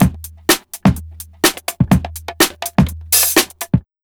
GRV125LOOP-R.wav